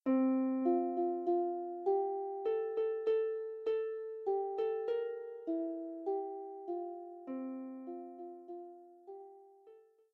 lever or pedal harp